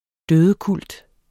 Udtale [ ˈdøːðə- ]